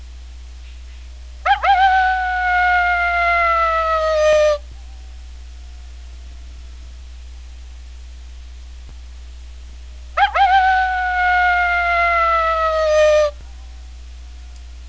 Lone Howl
lonhowl.wav